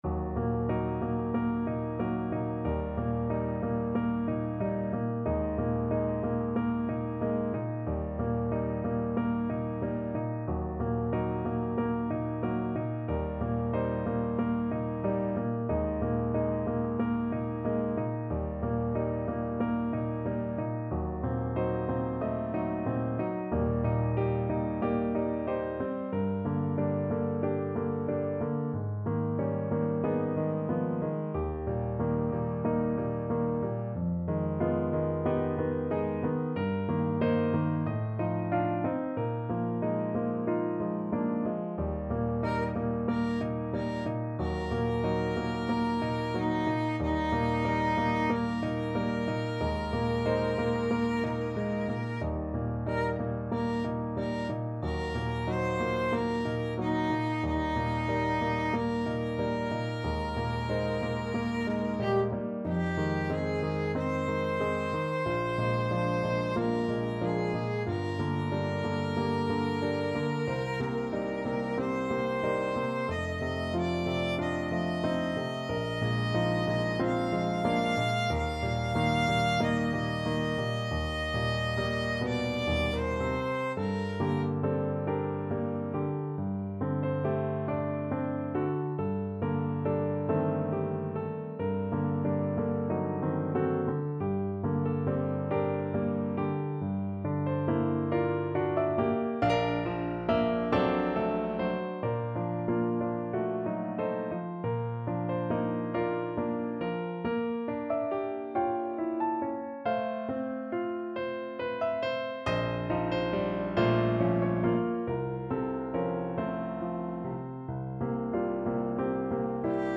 Violin version
4/4 (View more 4/4 Music)
Andante (=46)
Classical (View more Classical Violin Music)